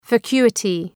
Προφορά
{væ’kju:ətı}